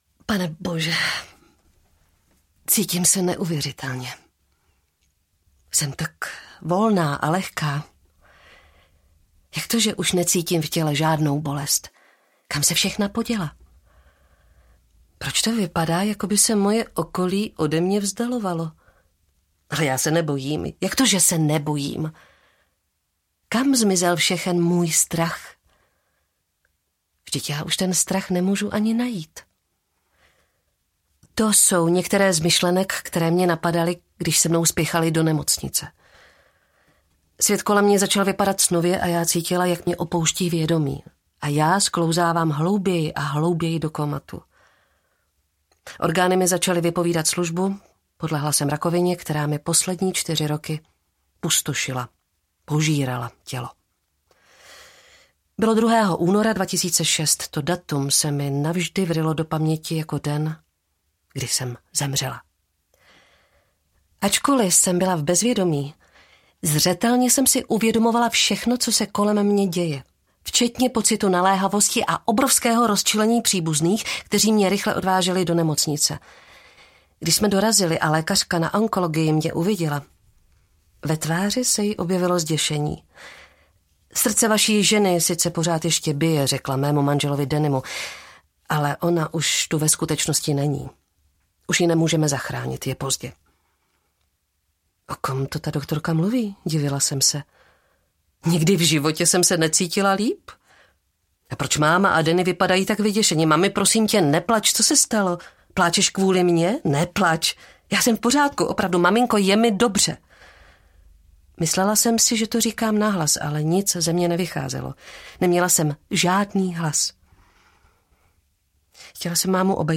Ukázka z knihy
musela-jsem-zemrit-audiokniha